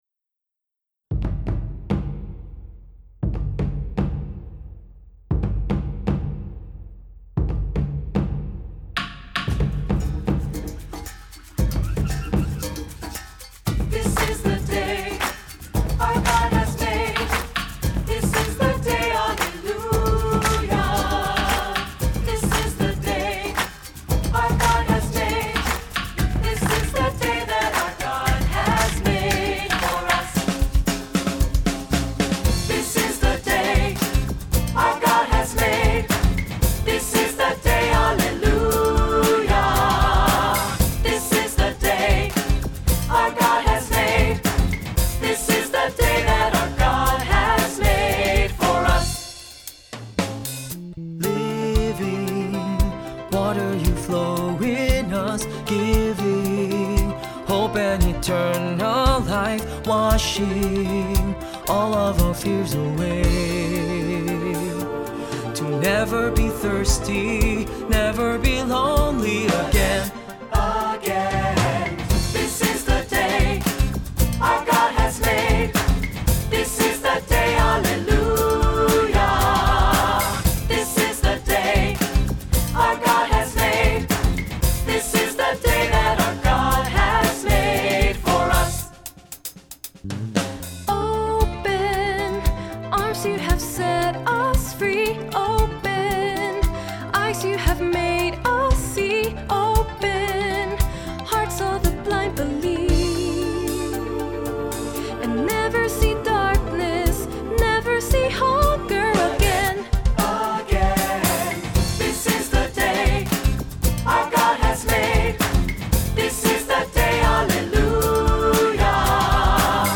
Accompaniment:      Piano
Music Category:      Christian
rhythmic and truly joyful song of praise